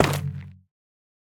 Minecraft Version Minecraft Version 25w18a Latest Release | Latest Snapshot 25w18a / assets / minecraft / sounds / block / shroomlight / break2.ogg Compare With Compare With Latest Release | Latest Snapshot